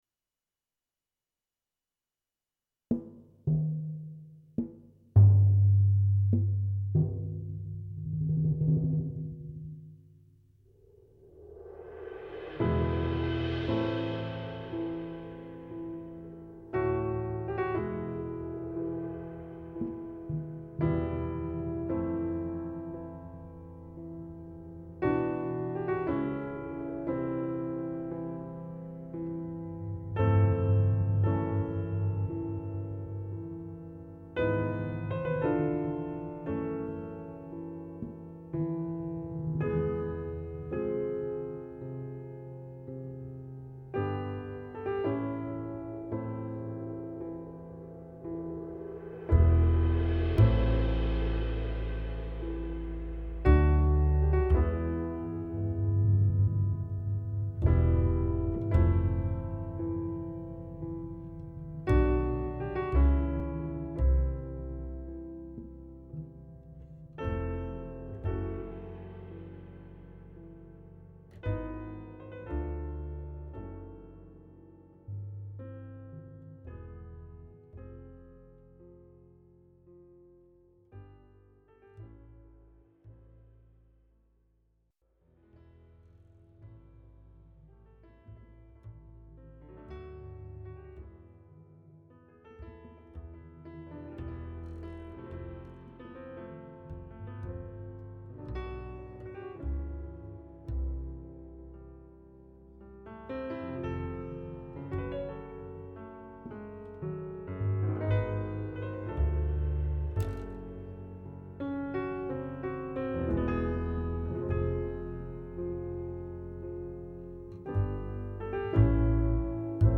Piano Trio